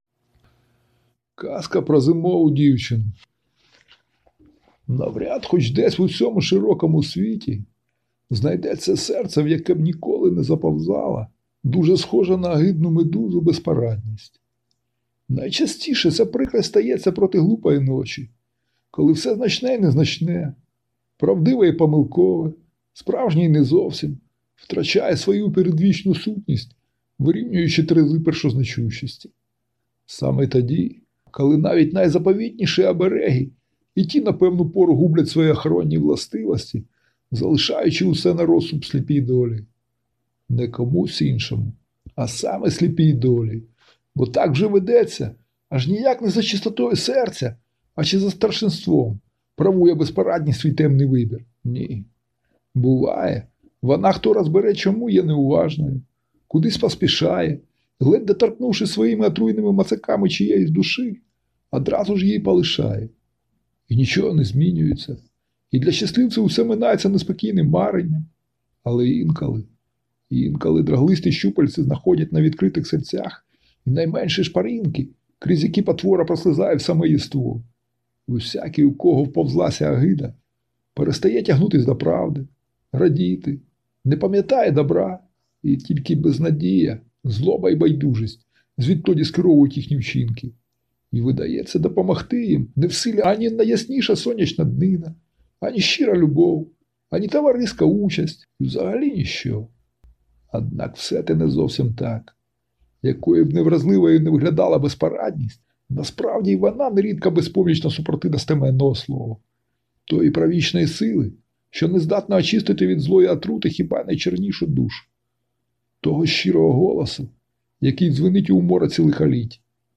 Слухати Аудіоказку про Зимову Дівчину ⭐ на сайті "Аудіоказка українською".
Жанр: Літературна казка